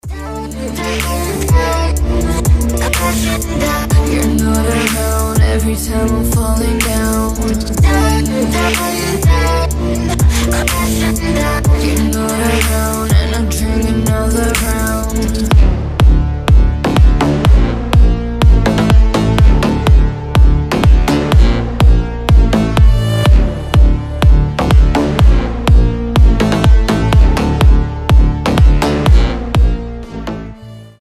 • Качество: 320, Stereo
громкие
женский вокал
мощные басы
чувственные
качающие
Стиль: trap, deep house